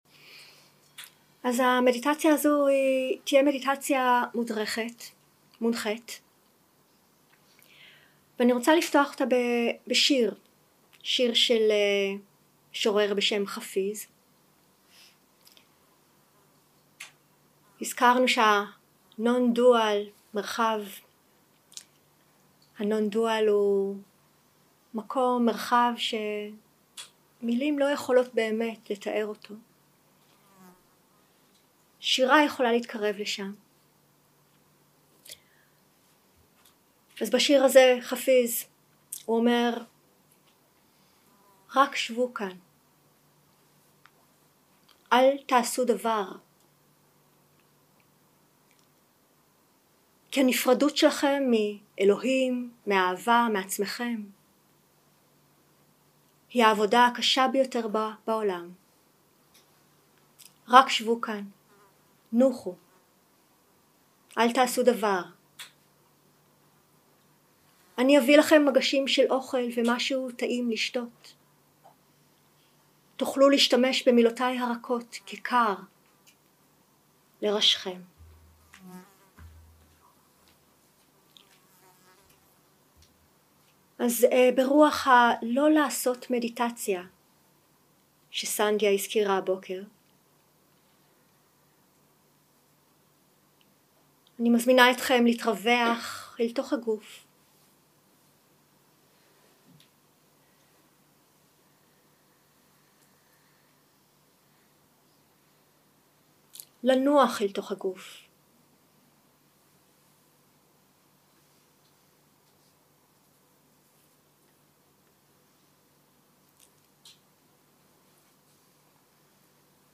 יום 2 – הקלטה 2 – צהריים – מדיטציה מונחית – לנוח אל תוך נוכחות
יום 2 – הקלטה 2 – צהריים – מדיטציה מונחית – לנוח אל תוך נוכחות 0:00 0:00 סוג ההקלטה: Dharma type: Guided meditation שפת ההקלטה: Dharma talk language: Hebrew